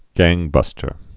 (găngbŭstər)